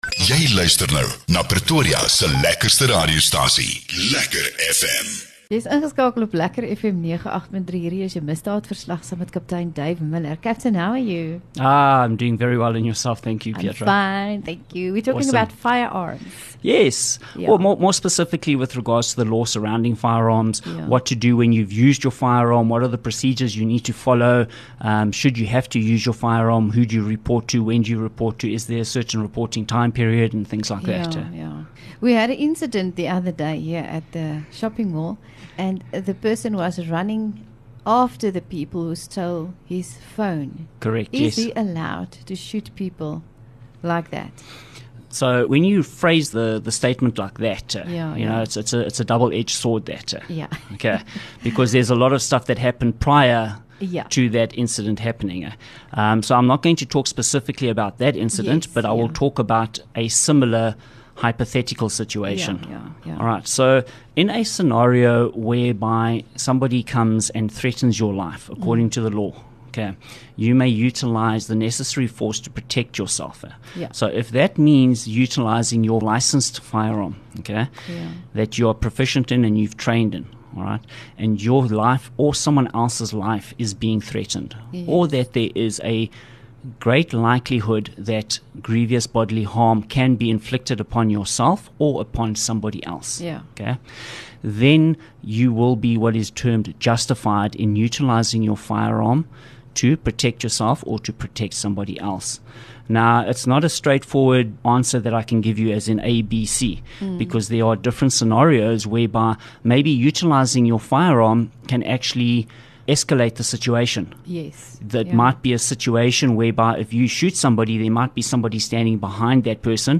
LEKKER FM | Onderhoude 25 Jul Misdaadverslag